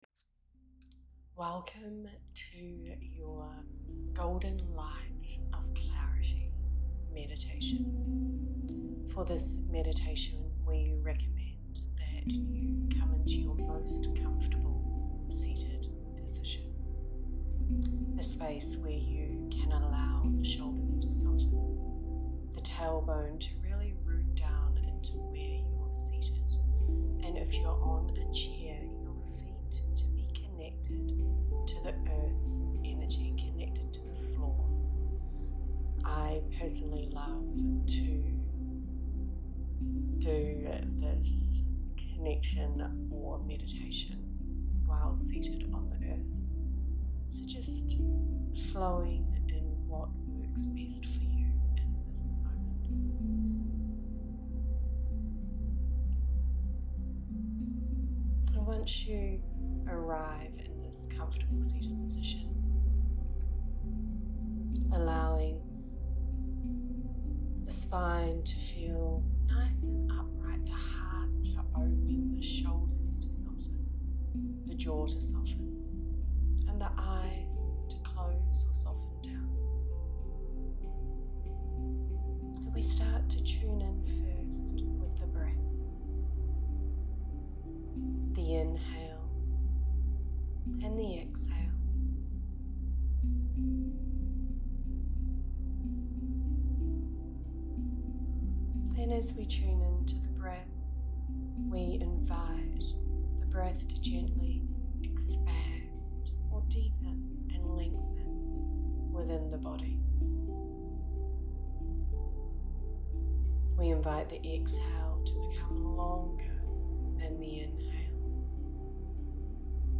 And this is the energy that this peaceful guided meditation brings.